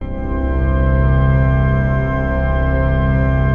PAD 46-1.wav